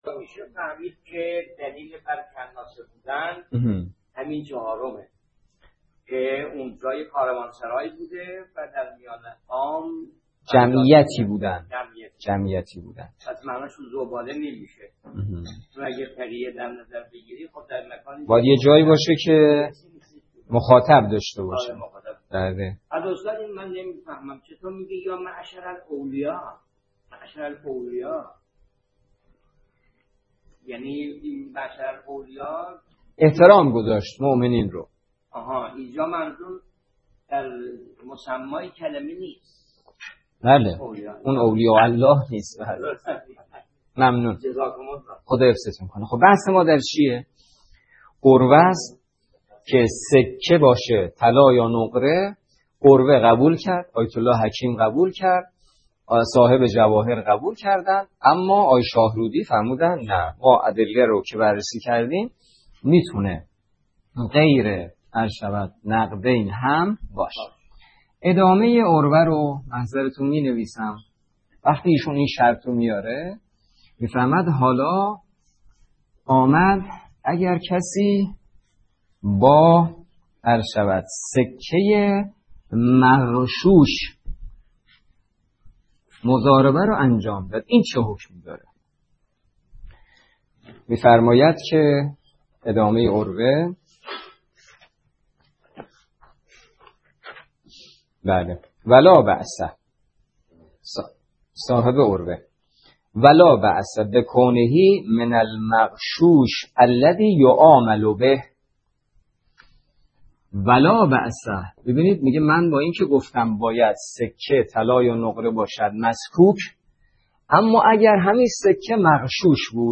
درس فقه
نماینده مقام معظم رهبری در منطقه و امام جمعه کاشان موضوع: فقه اجاره - جلسه هجدهم